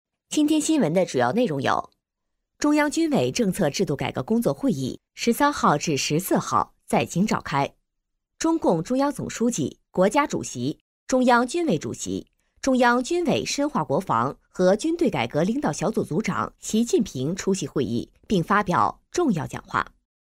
Chinese mandarin female voice overs. Group A       Chinese mandarin male voice overs.
Chinese voice over